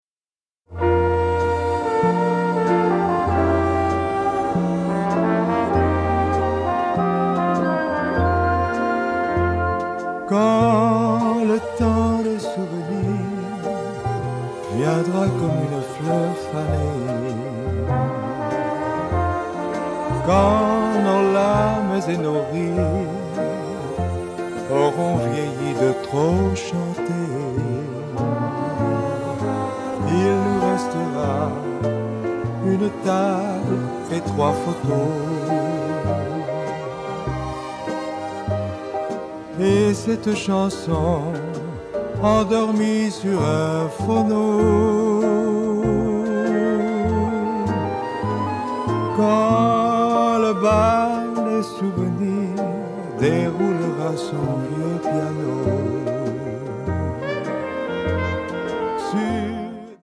wistful ballad